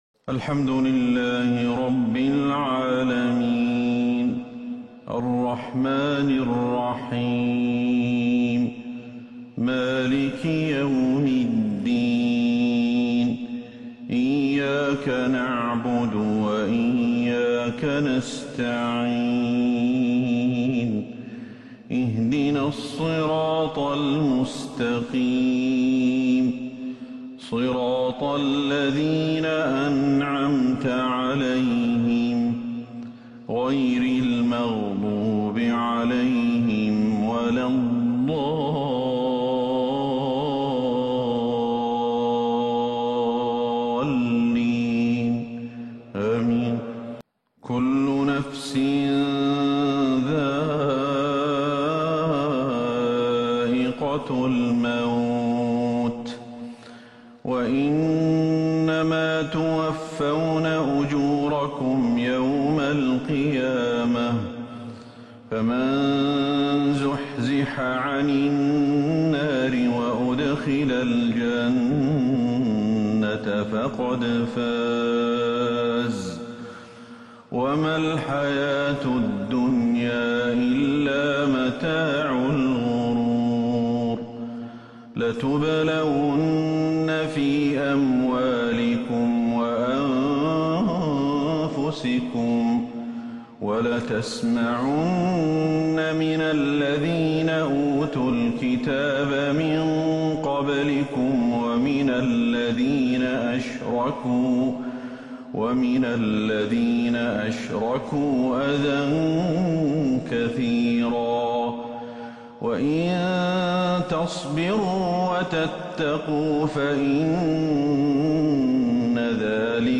فجر الجمعة 2-6-1442هـ من سورة آل عمران | Fajr prayer from Surah Aal-i-Imraan 15/1/2021 > 1442 🕌 > الفروض - تلاوات الحرمين